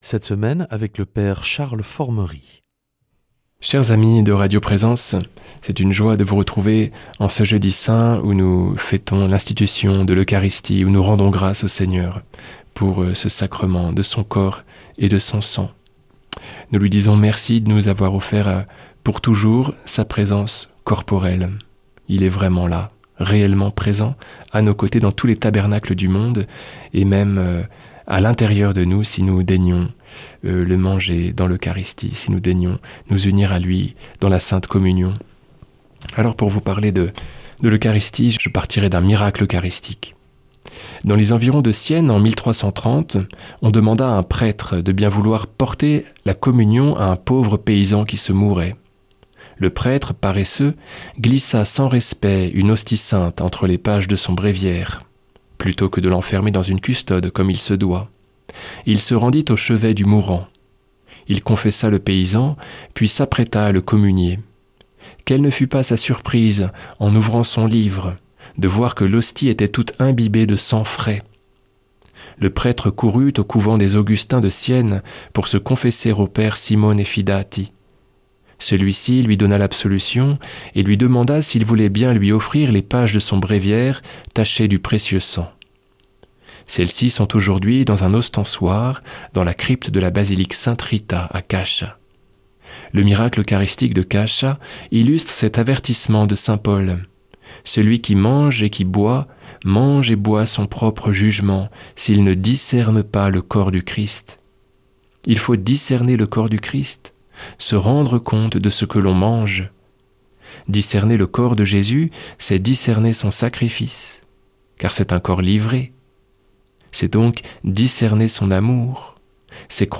jeudi 2 avril 2026 Enseignement Marial Durée 10 min